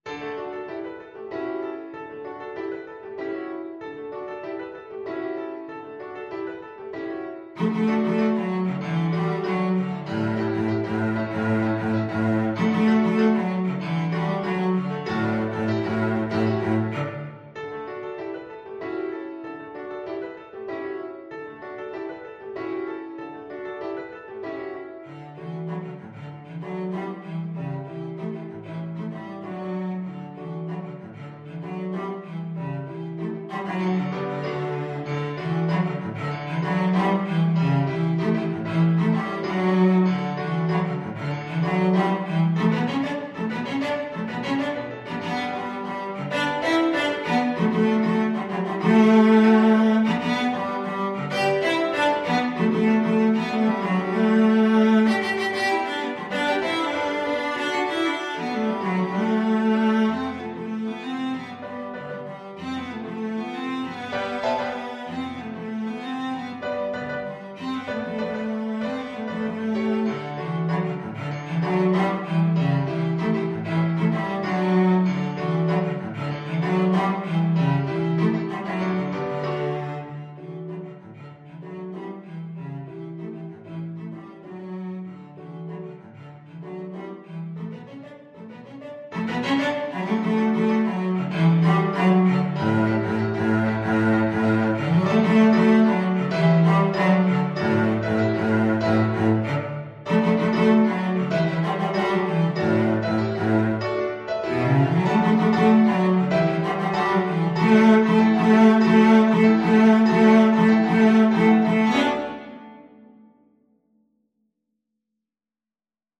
4/4 (View more 4/4 Music)
A3-G5
Classical (View more Classical Cello Music)